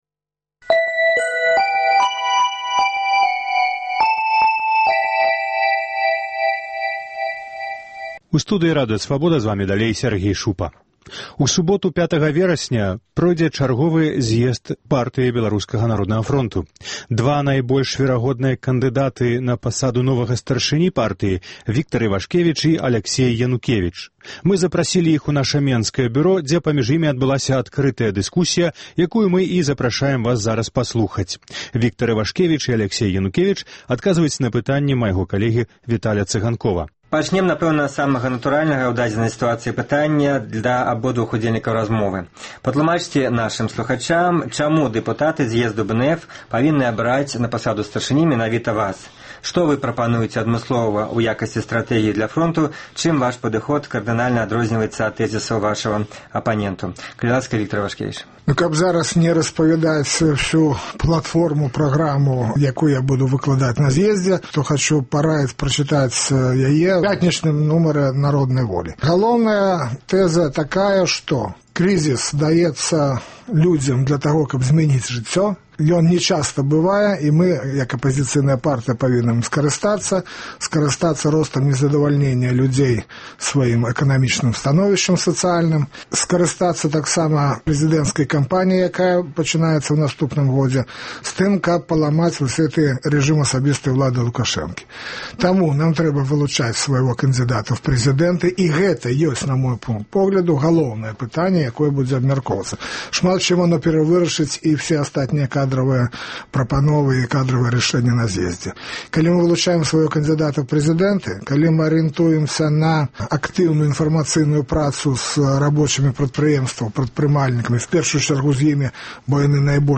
Дэбаты